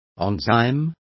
Complete with pronunciation of the translation of enzymes.